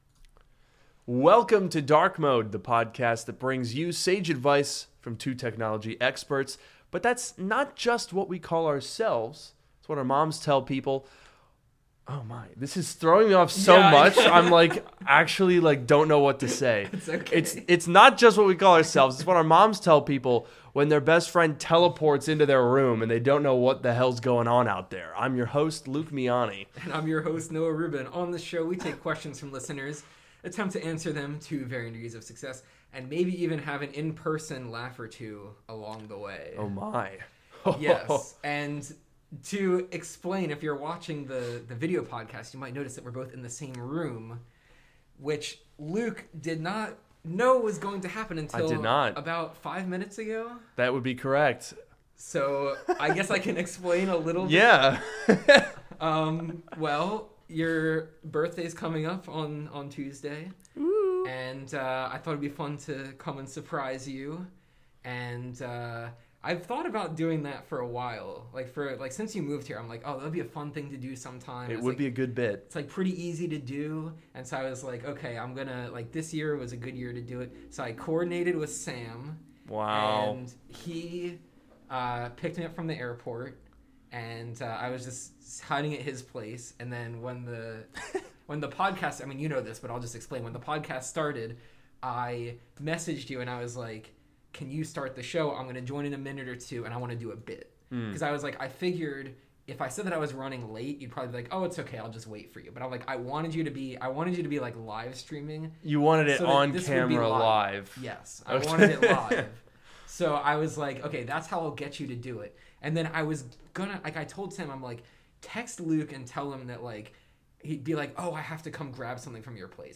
This is Drk Mode, the podcast that brings you sage advice from two technology experts.